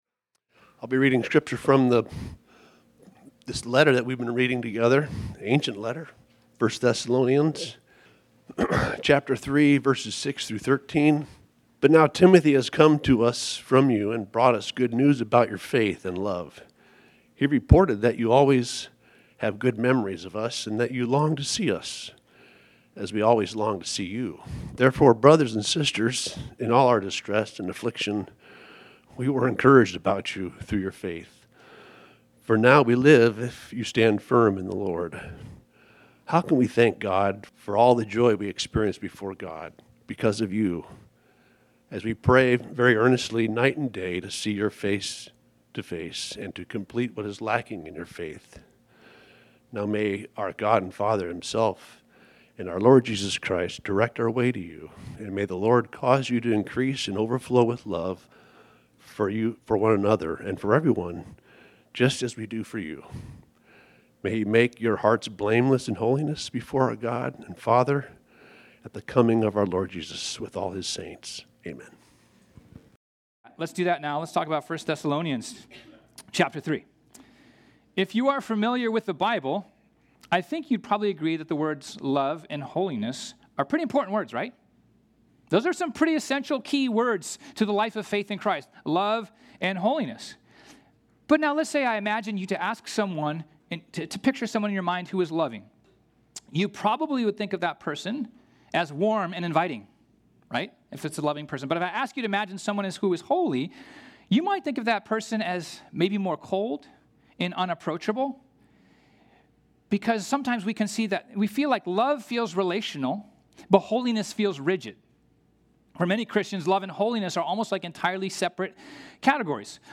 This sermon was originally preached on Sunday, July 13, 2025.